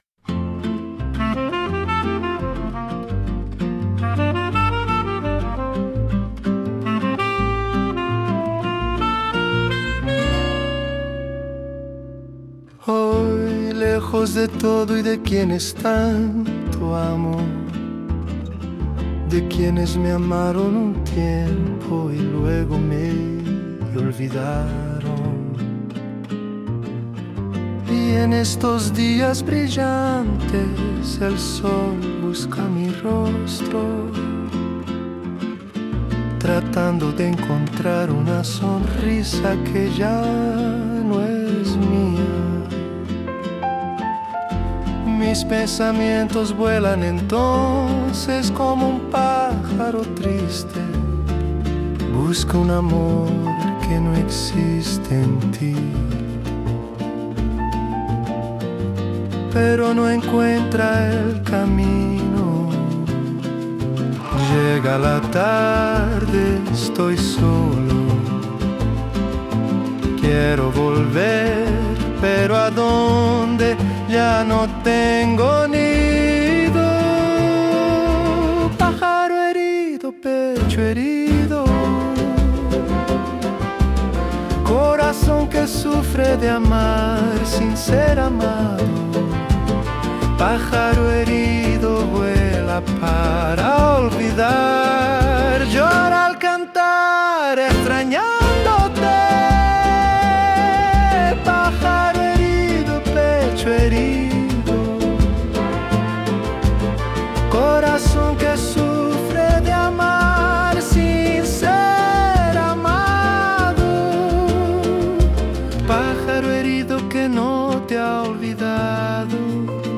música, arranjo e voz: IA